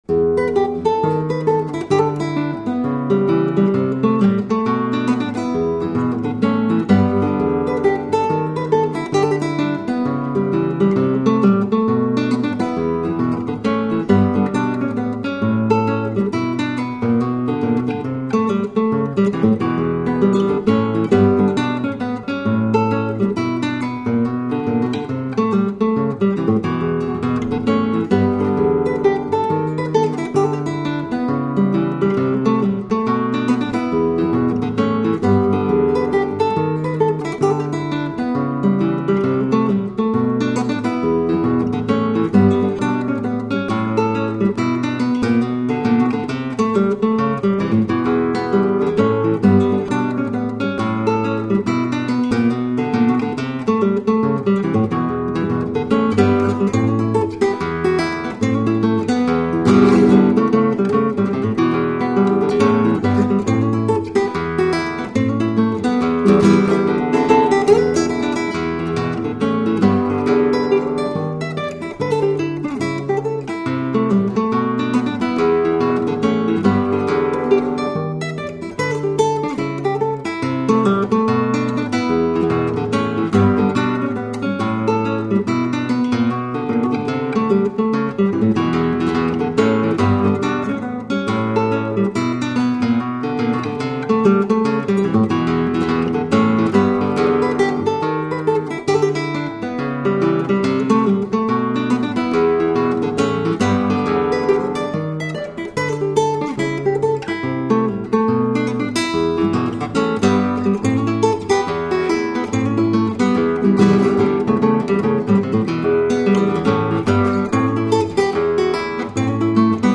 Genere: Country - Blues - Classico per chitarra solo
chitarra classica